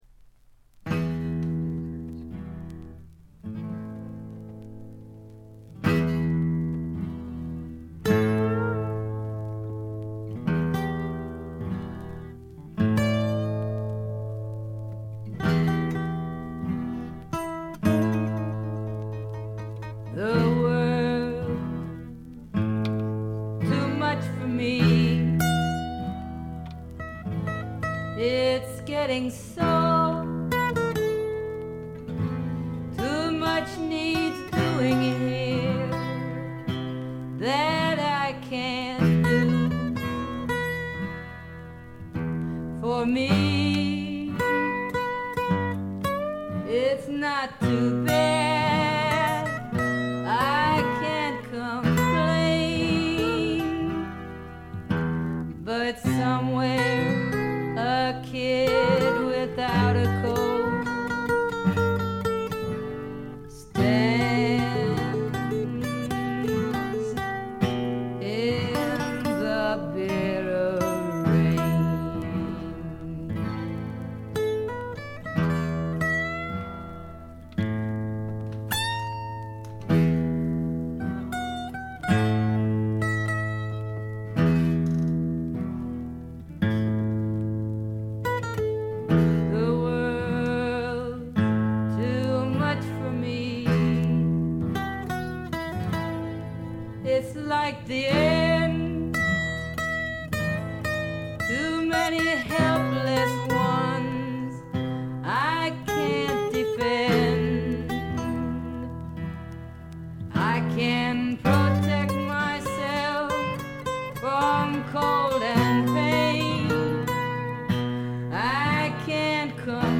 存在感抜群の強靭なアルト・ヴォイスが彼女の最大の武器でしょう。
試聴曲は現品からの取り込み音源です。
Vocals, Guitar